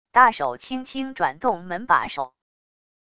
大手轻轻转动门把手wav音频